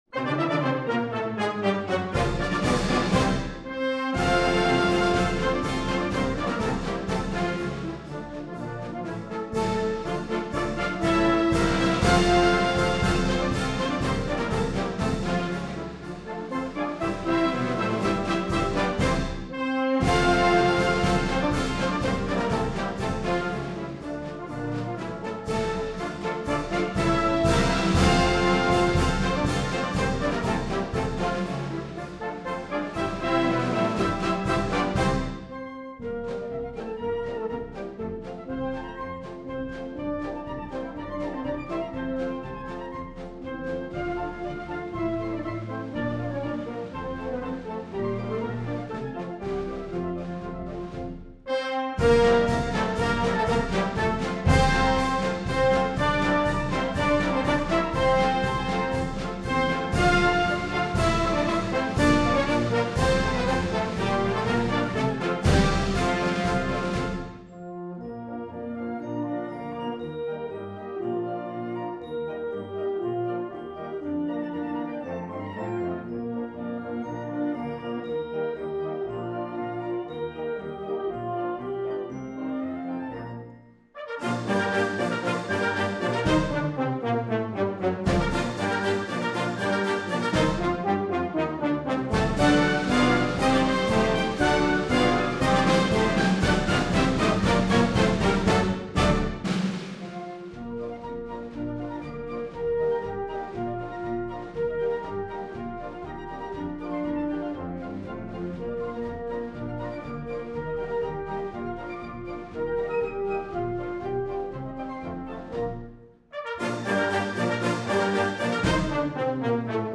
La Flor de Sevilla March from The Complete Marches of John Philip Sousa: Vol. 7